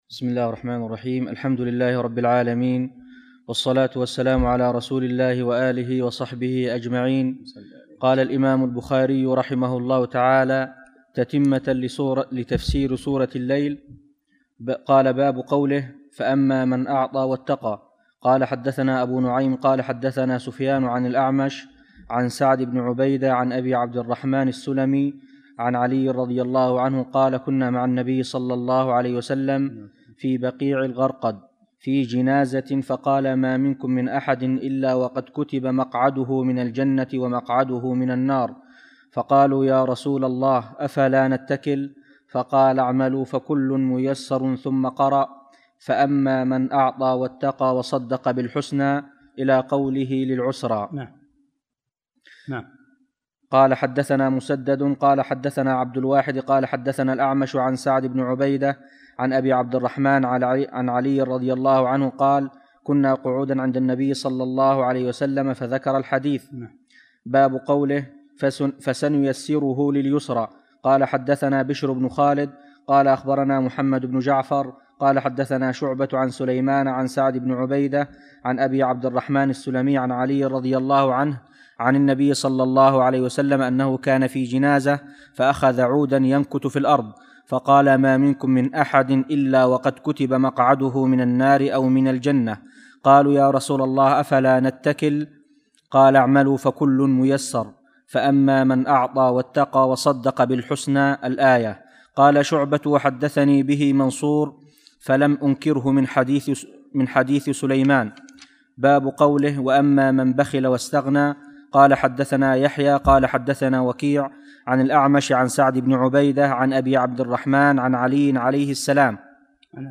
53- الدرس الثالث والخمسون